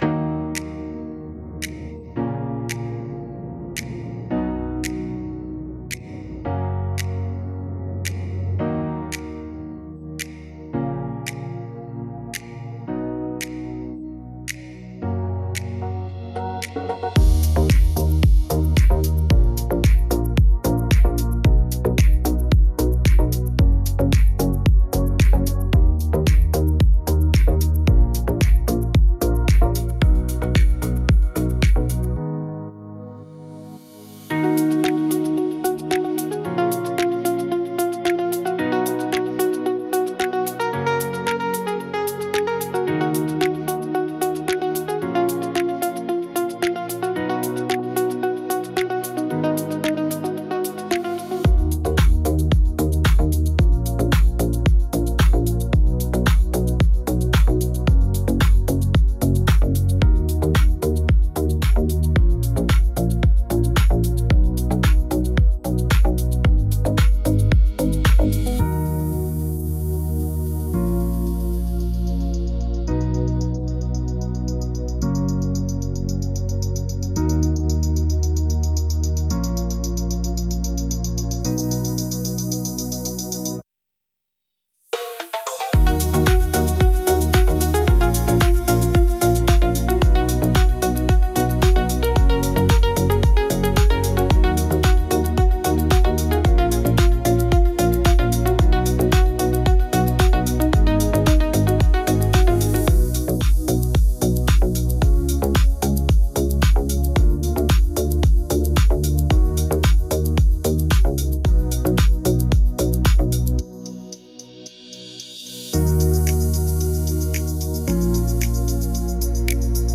Tropical House · 112 BPM · Eng